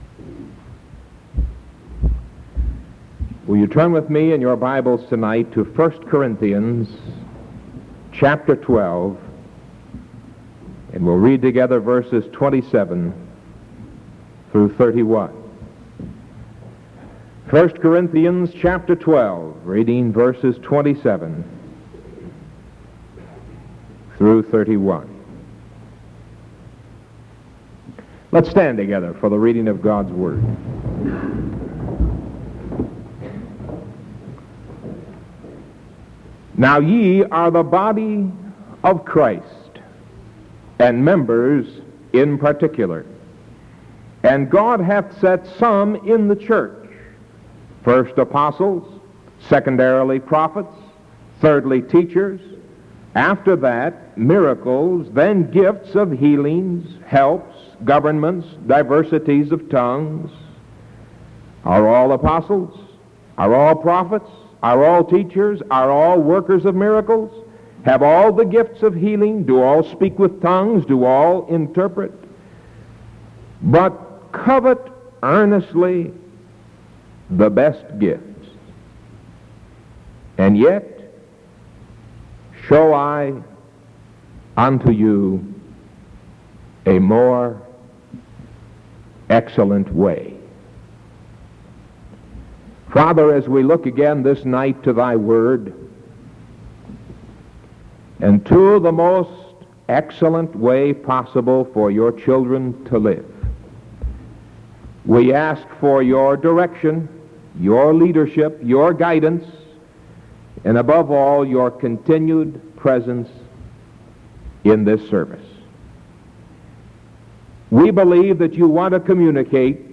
Sermon June 24th 1973 PM